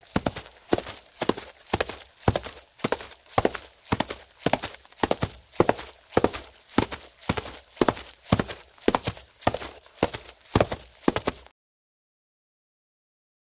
На этой странице собраны натуральные звуки копыт лошадей: от размеренного шага до энергичного галопа.
Средняя скорость скачущей лошади